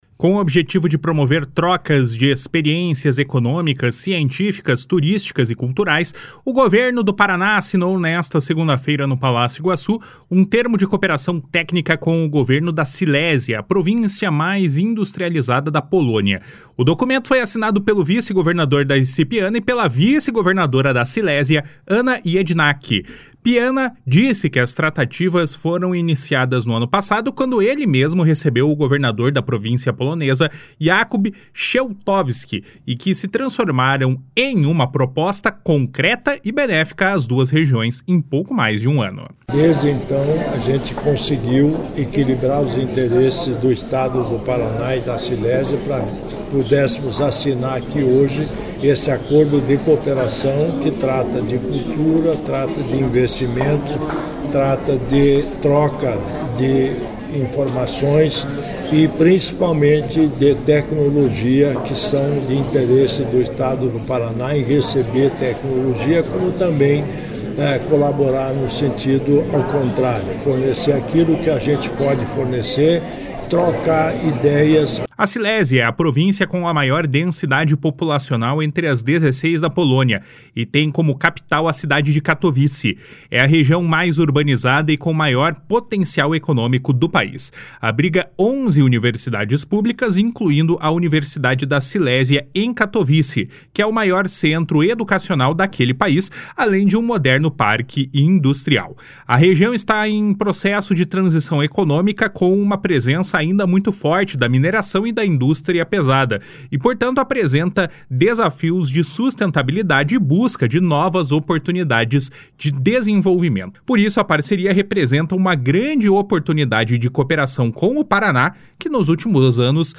// SONORA DARCI PIANA //
Na avaliação da cônsul-geral da Polônia em Curitiba, Marta Olkowska, a assinatura do termo de cooperação permitirá que a Polônia esteja política e economicamente mais presente no Estado. // SONORA MARTA OLKOWSKA //